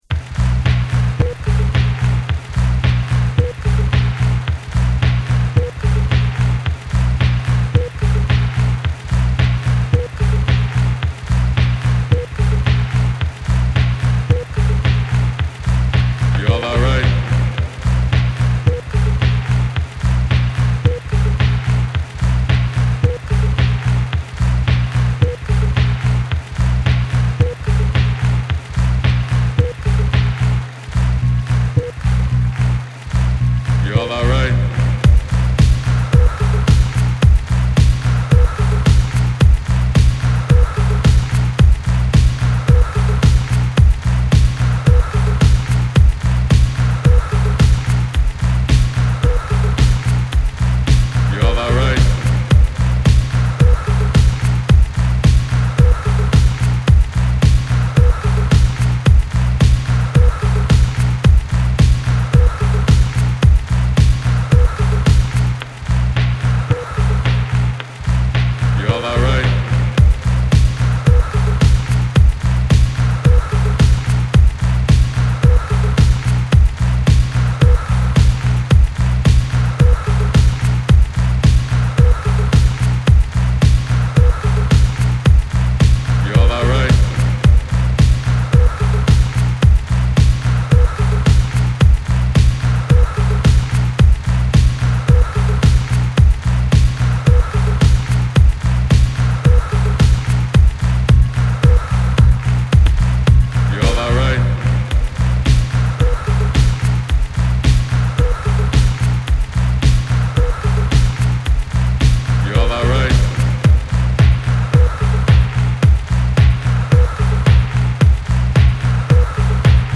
タイトル通りパーティー・ヴァイブ溢れるディスコ・サンプル・ビートダウン3TRKを展開！